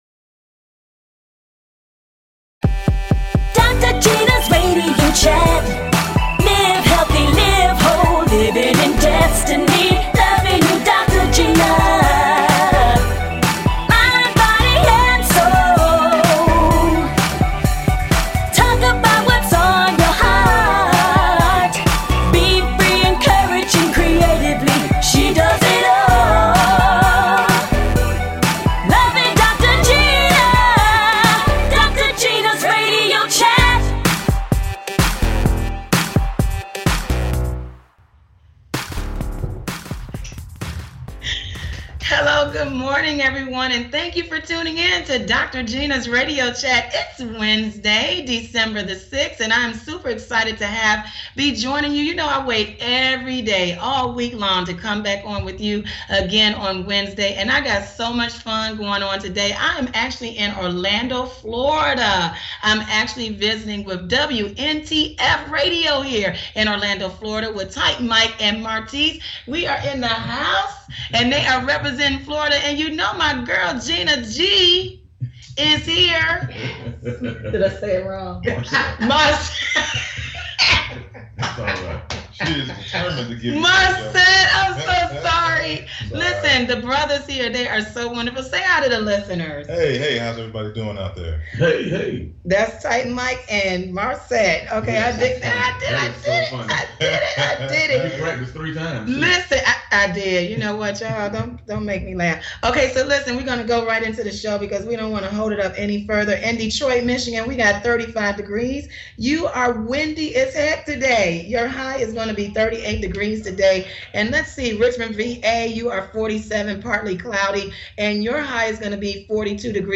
Talk Show
EVERYONE IS WELCOME to join in the conversation!